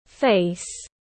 Face /feɪs/